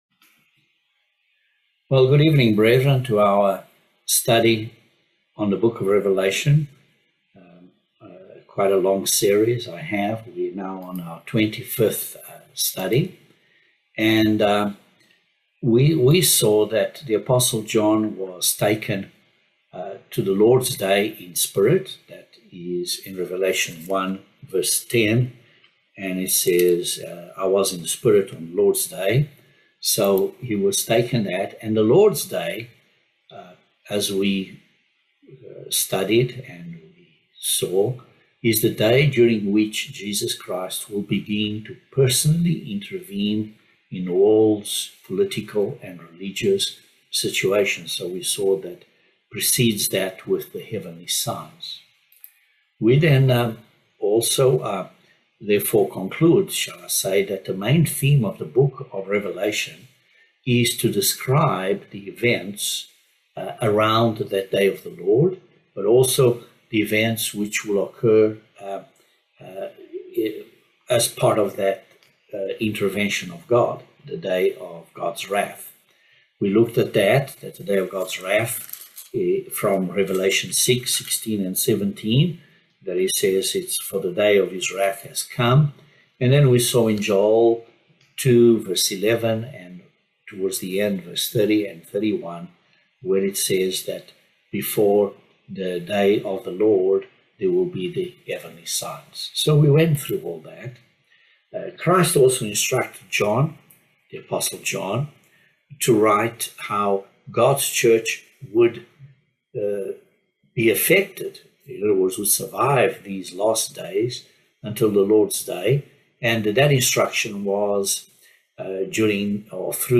Bible Study No 25 of Revelation